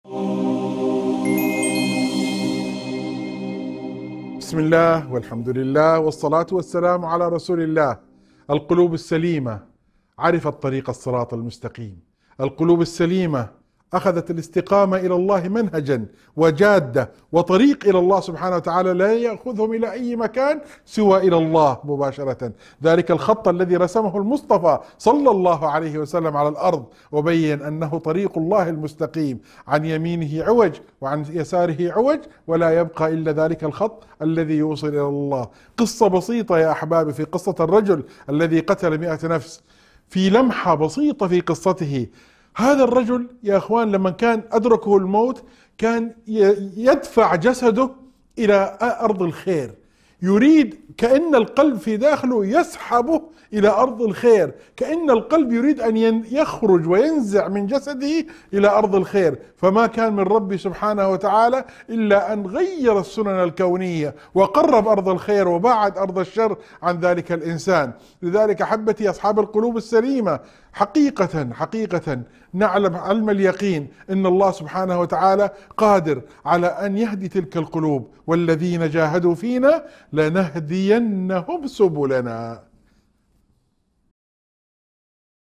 موعظة مؤثرة تتحدث عن صفات القلب السليم الذي يستقيم على طريق الله، وتذكر بقصة الرجل التائب لتأكيد سعة رحمة الله وقدرته على هداية القلوب. تهدف إلى ترسيخ اليقين وتقريب المؤمنين من خالقهم.